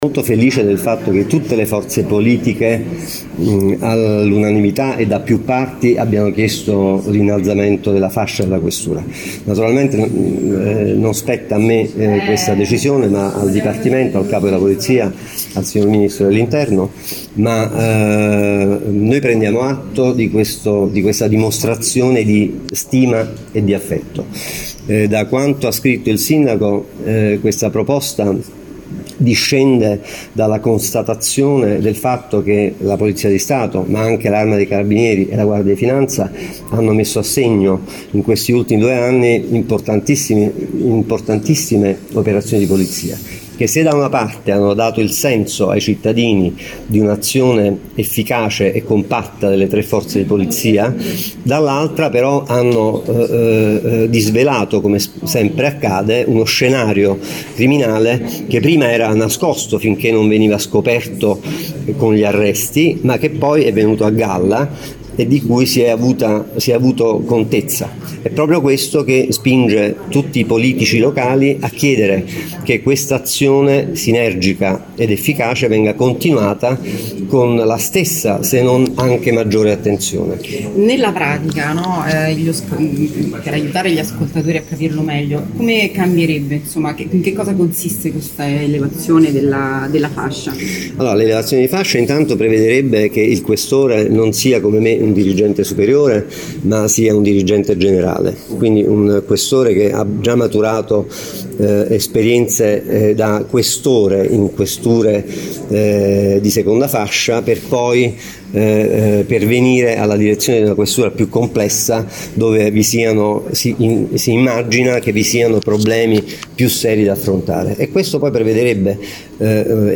Si è detto soddisfatto della richiesta il Questore Michele Spina: “La decisione non spetta a me, ma questa proposta discende, come ha spiegato il sindaco, dal lavoro svolto dalla Questura e dalle altre forze dell’ordine”, ha detto il questore spigando anche in che cosa consiste l’elevazione di fascia della Questura.
questore-fascia-questura.mp3